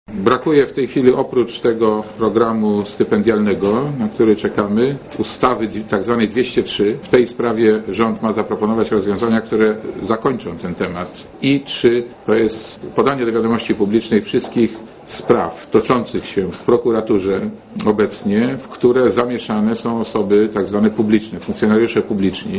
mówi Marek Borowski
Na konferencji prasowej lider SdPl przypomniał, że te trzy postulaty zgłoszone jeszcze przed udzieleniem przez Socjaldemokrację w czerwcu poparcia Markowi Belce dotyczą stworzenie funduszu stypendialnego dla dzieci i młodzieży ze szkół średnich i gimnazjalnych, rozwiązania sprawy tzw. ustawy 203 i podania do wiadomości publicznej wszystkich spraw toczących się w prokuraturze, w które zamieszani są funkcjonariusze publiczni.